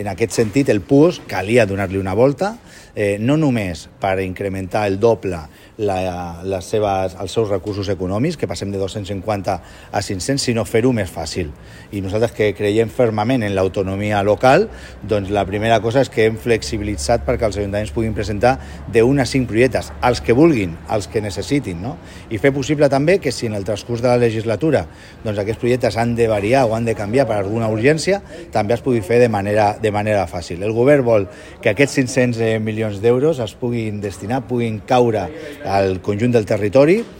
Xavier Amor ha explicat que el nou model de PUOSC dona “més facilitats i flexibilitat” als consistoris per escollir els projectes que volen subvencionar…